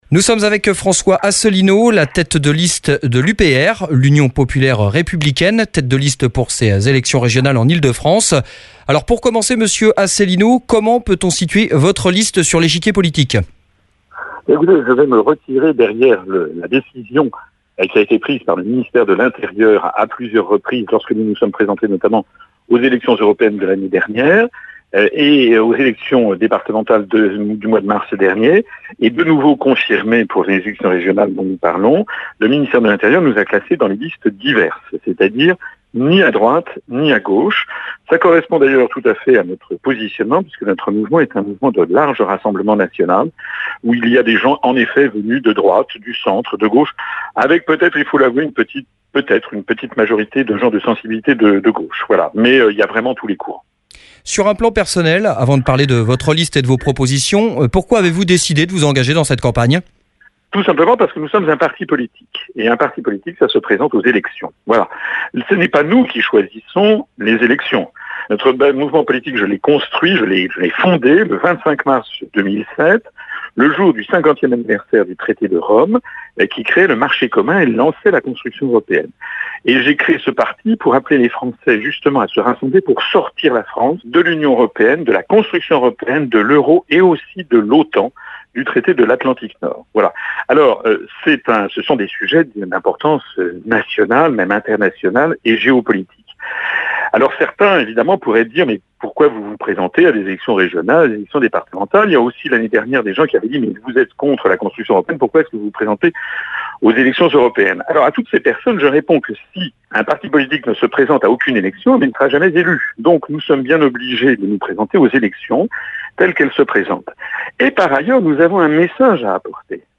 Entretien de François Asselineau, tête de liste de l’UPR aux élections régionales en Ile-de-France sur Rézo Radio